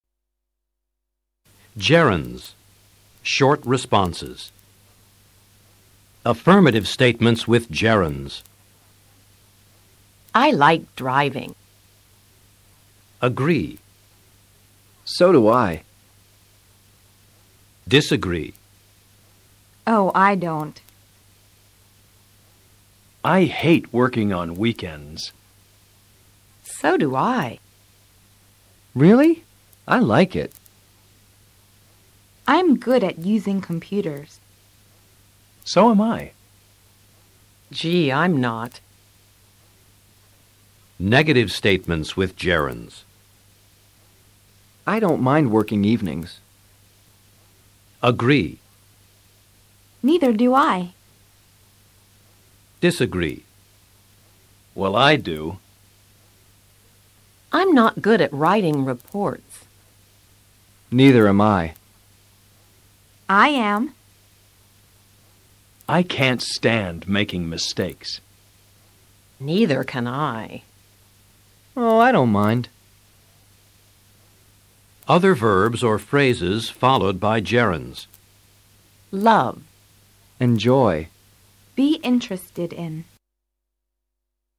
Escucha a los profesores y presta atención al uso de los GERUNDIOS y de las RESPUESTAS CORTAS en estas oraciones.